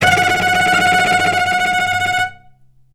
vc_trm-F#5-mf.aif